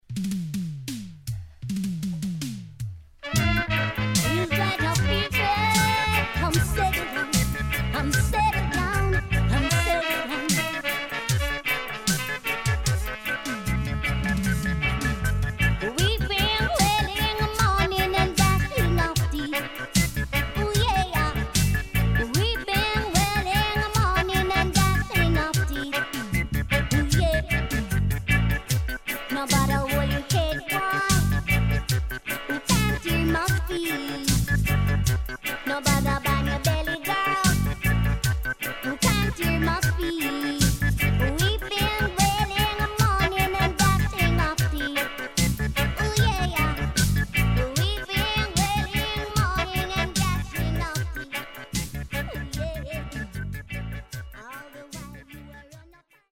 HOME > REISSUE [REGGAE / ROOTS]